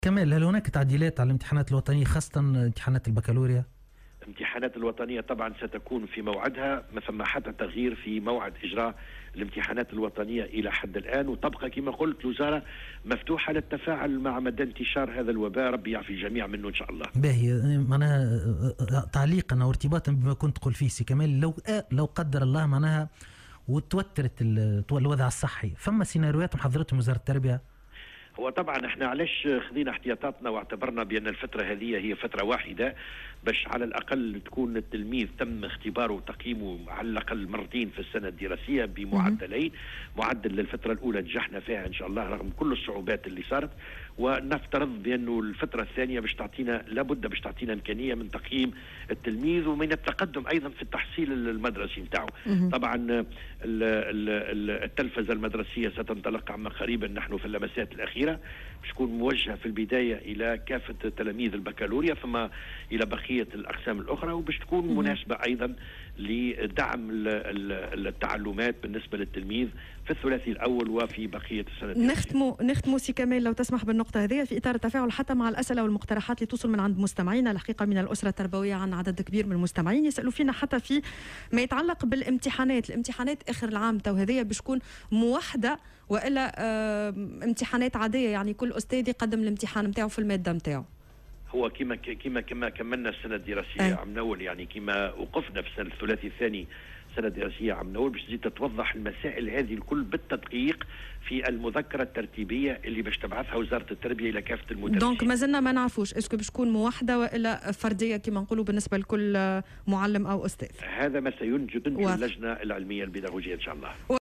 وأوضح في مداخلة له اليوم على "الجوهرة أف أم" أن جميع الاحتمالات تبقى واردة بالنظر لمدى تطور الوضع الوبائي.